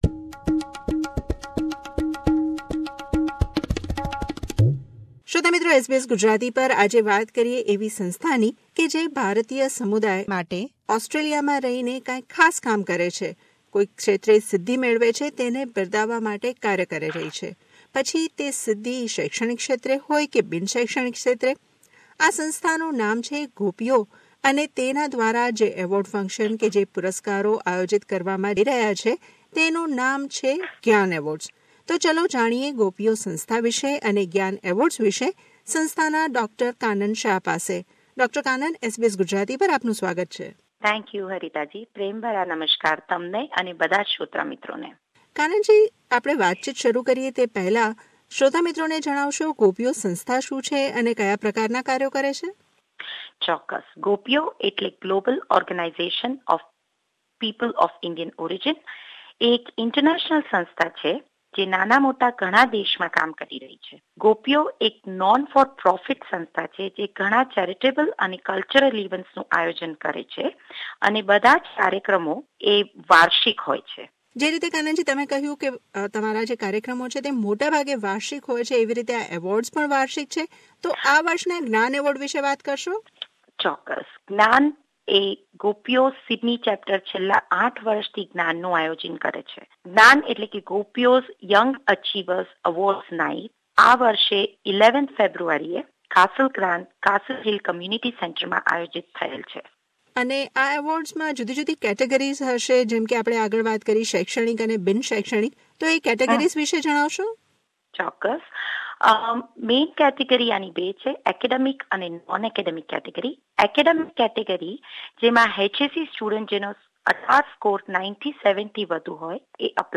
વાતચીત